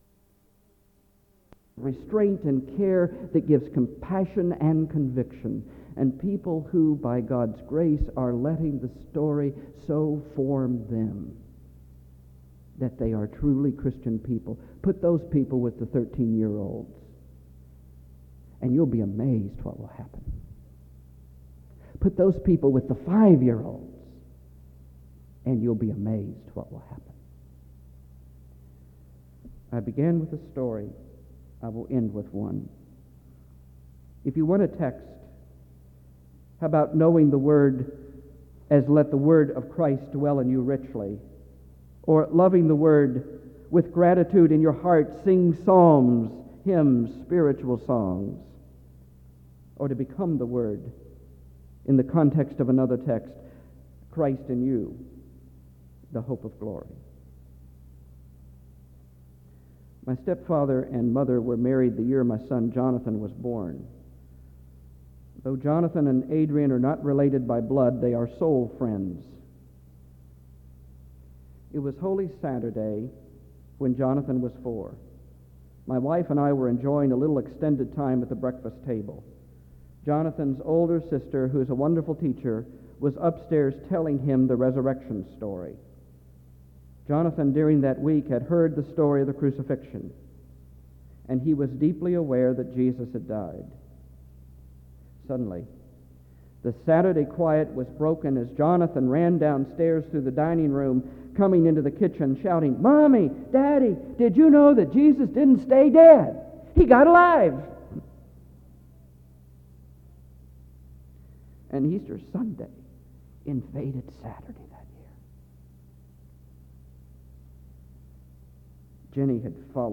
SEBTS Kendrick-Poerschke Lecture
SEBTS Chapel and Special Event Recordings